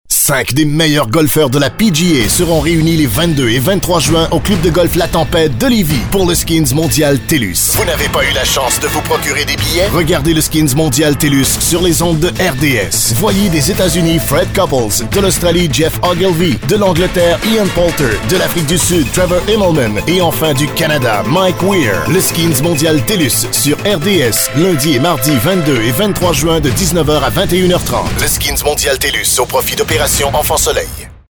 Voix Hors Champ narrateur professionnel producteur radio
Sprechprobe: Sonstiges (Muttersprache):